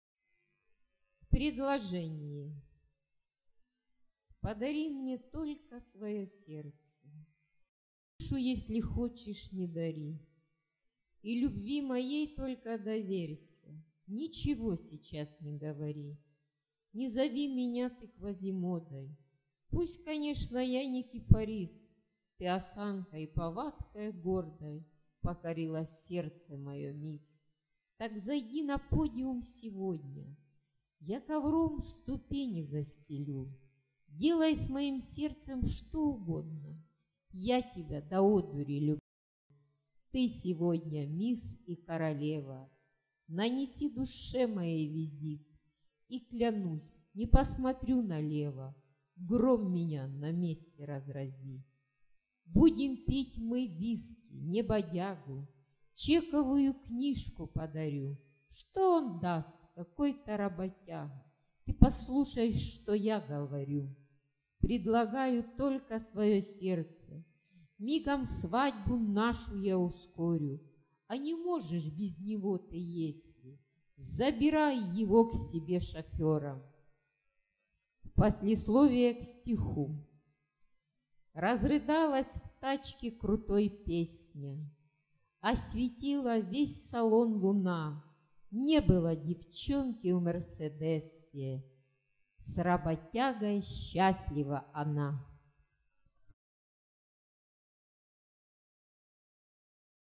Стих начитан Автором